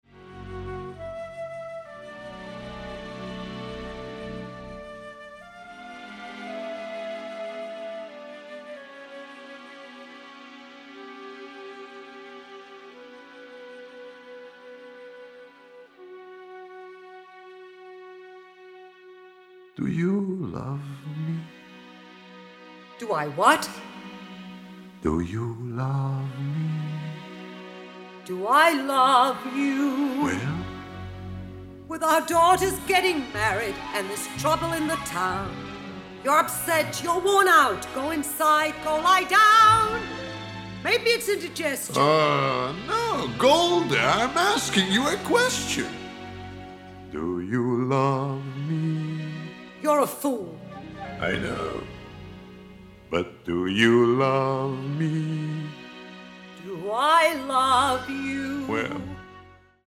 virtuoso violin soloist